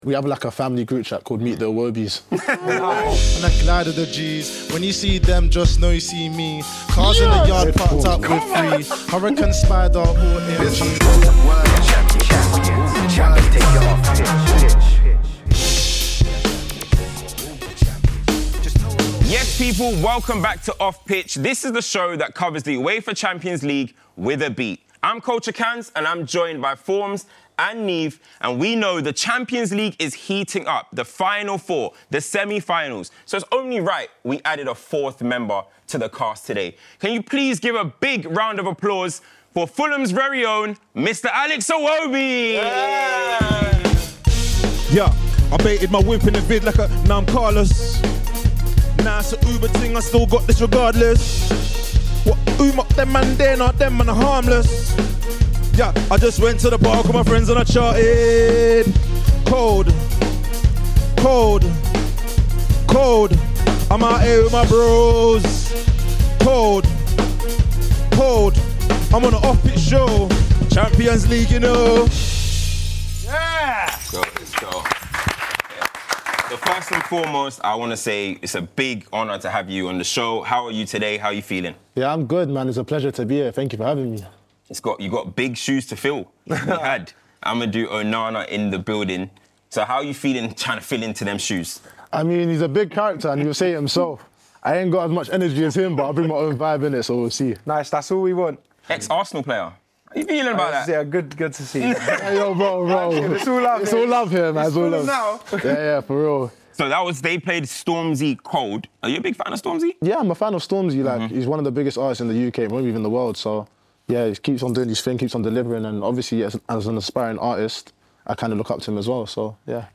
This is Off Pitch: The UEFA Champions League with a beat. Where UCL coverage meets the timeline’s conversations – fuelled by music.